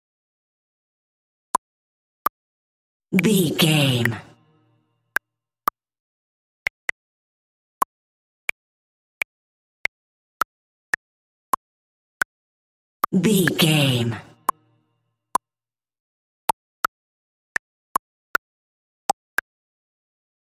Cartoon Bubble Pops
Sound Effects
Atonal
funny
cheerful/happy